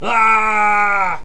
falling1.ogg